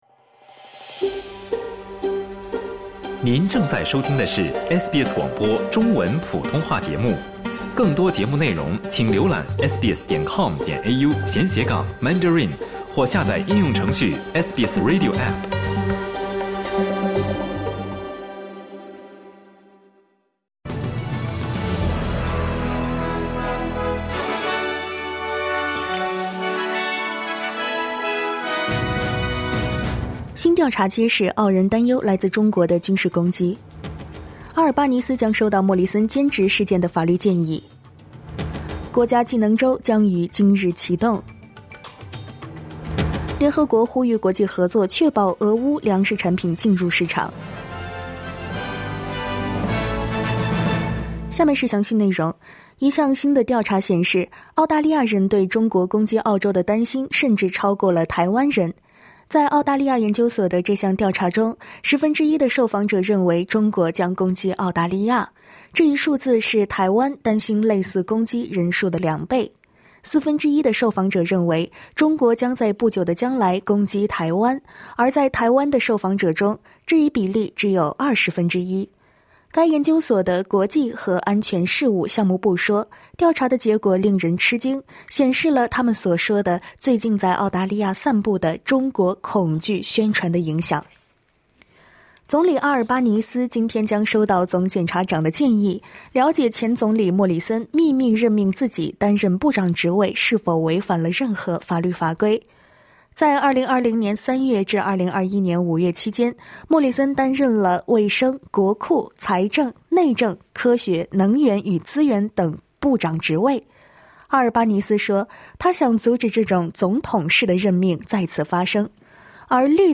SBS早新闻（8月22日）